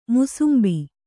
♪ musumbi